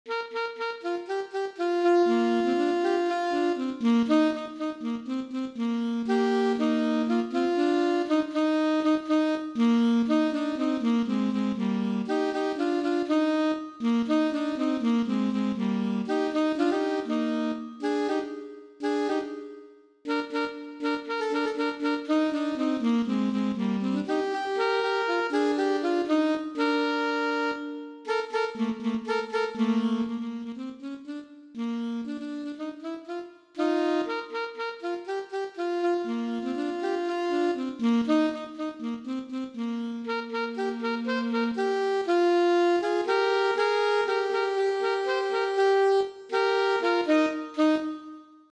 • Easy-Medium